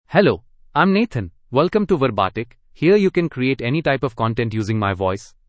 Nathan — Male English (India) AI Voice | TTS, Voice Cloning & Video | Verbatik AI
Nathan is a male AI voice for English (India).
Voice sample
Male
Nathan delivers clear pronunciation with authentic India English intonation, making your content sound professionally produced.